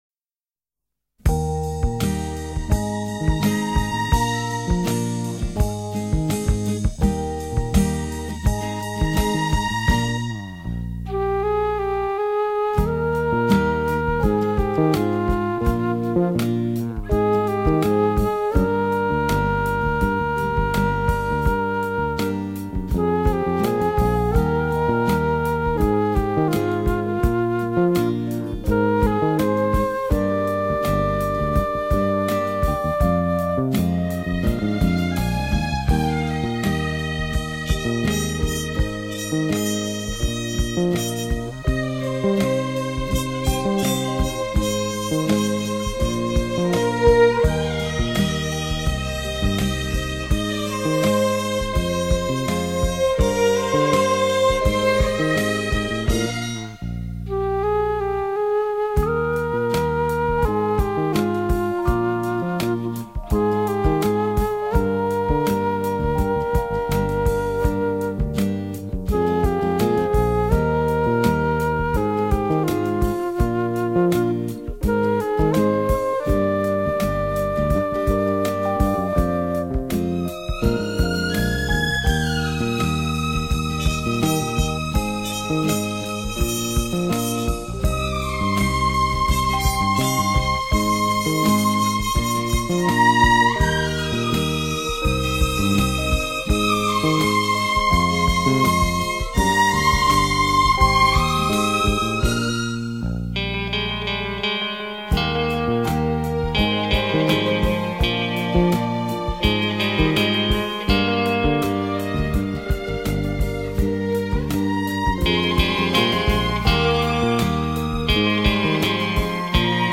充满了一种浪漫的情调
音色华丽而纯朴，效 果极佳！